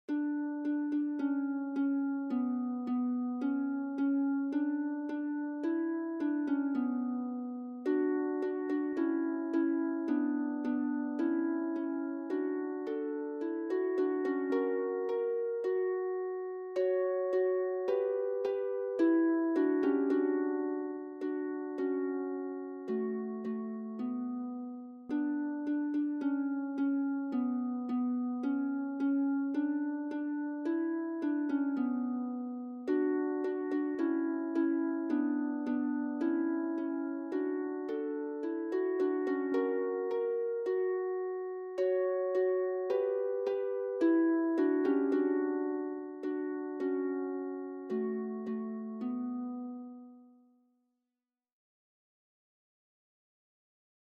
Sacred ; Hymn (sacred)
SAB OR SSA OR TTB (3 mixed OR equal voices )
Tonality: D major